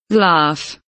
laugh kelimesinin anlamı, resimli anlatımı ve sesli okunuşu